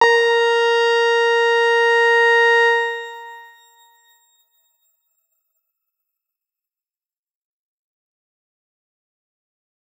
X_Grain-A#4-pp.wav